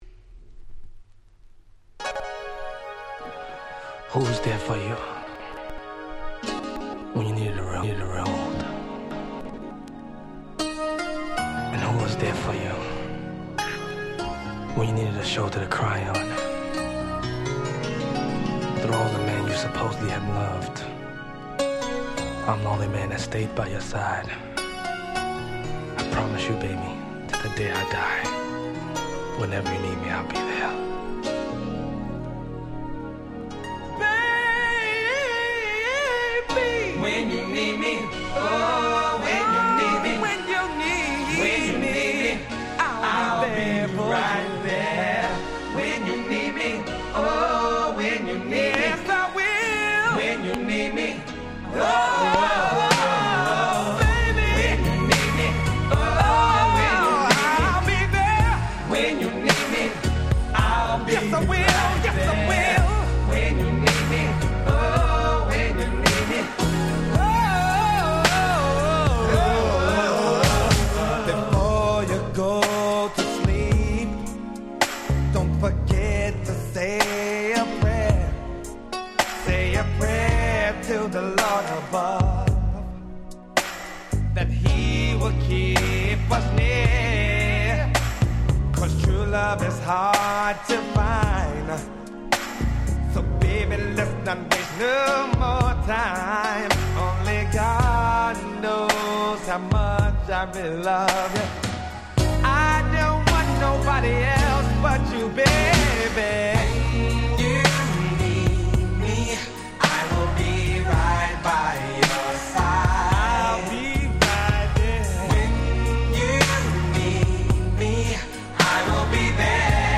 93' Nice R&B/New Jack Swing LP !!
A面はハネ系New Jack Swing中心、B面は激甘Slow中心。